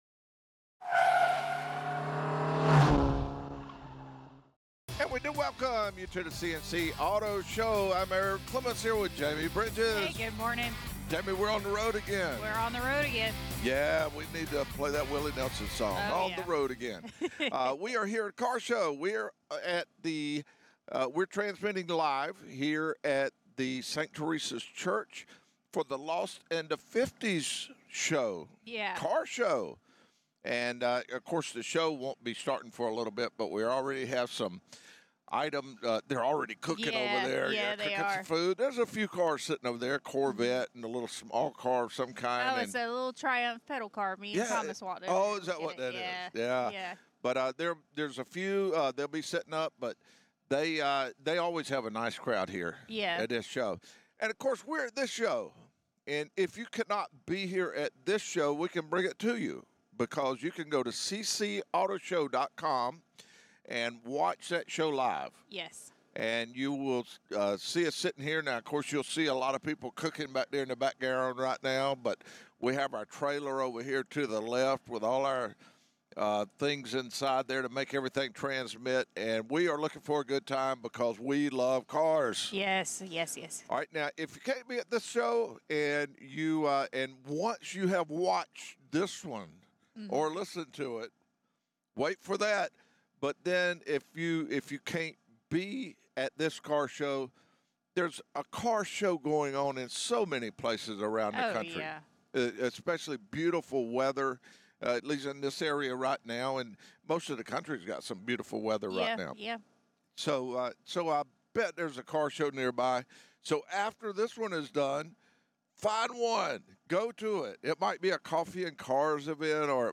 #1955 Live from the Lost in the Fifties Car Show